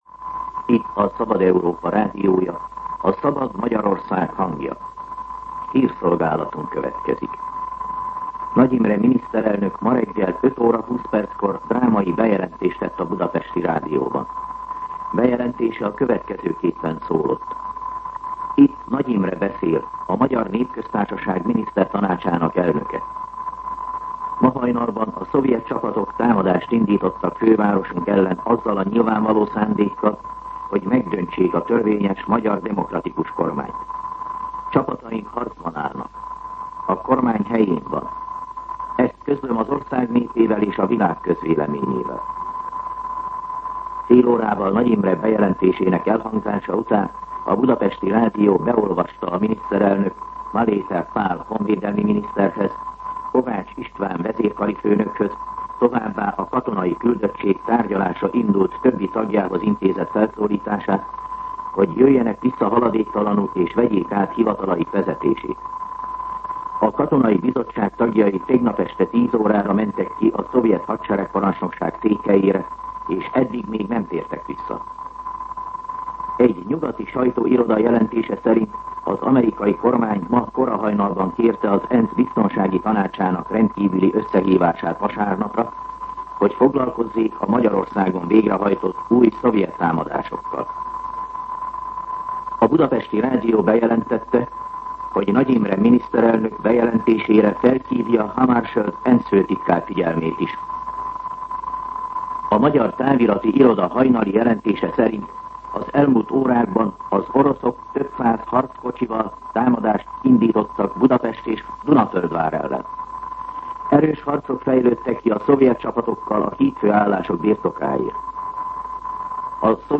Hírszolgálat
MűsorkategóriaHírszolgálat